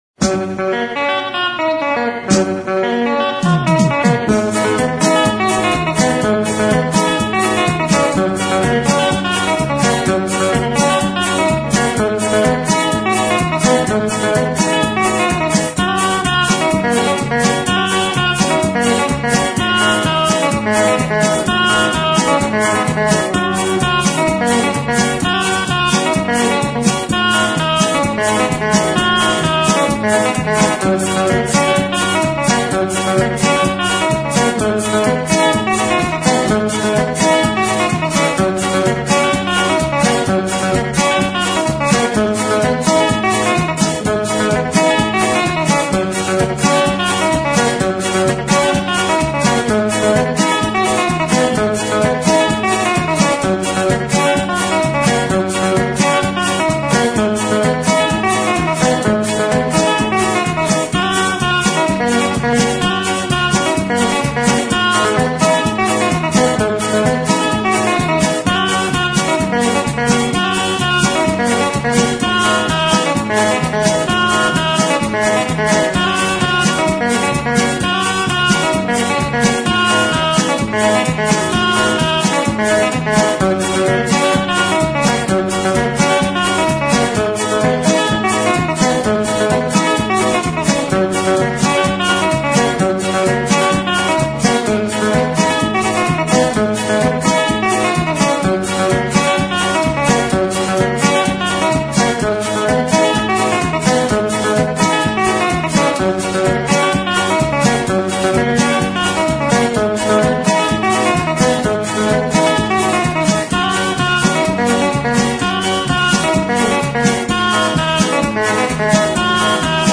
Guitarradas